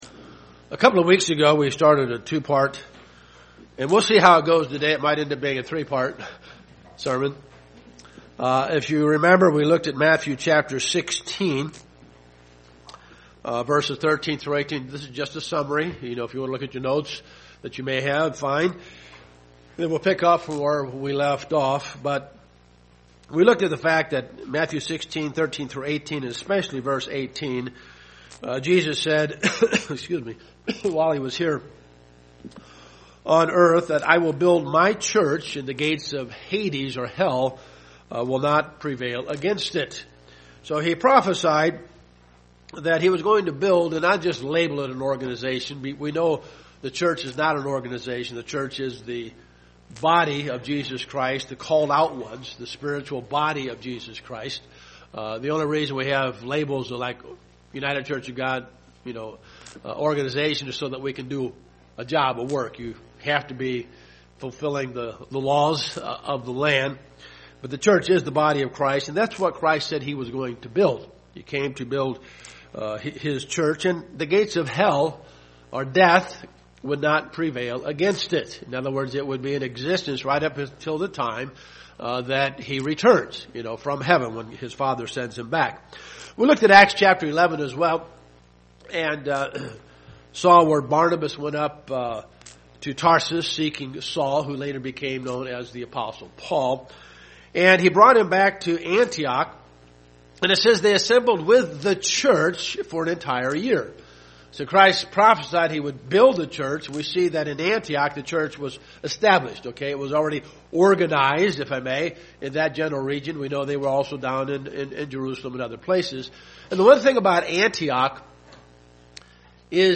This is part two of a three part sermon series on the attributes of Jesus Christ.